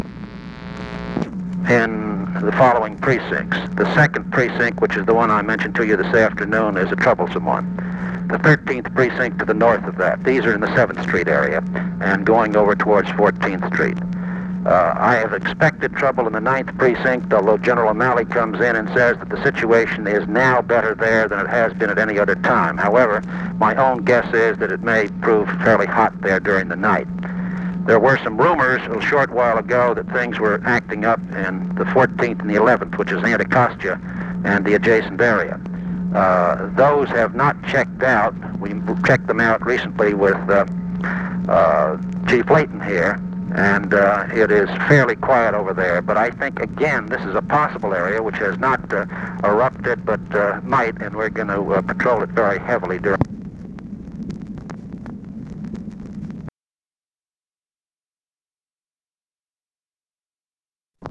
Conversation with CYRUS VANCE, April 6, 1968
Secret White House Tapes